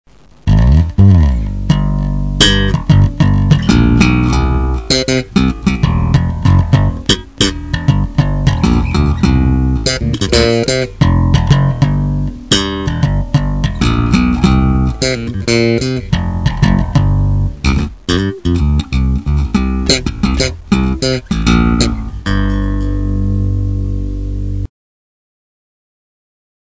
04 - Bass - Groove Nr.01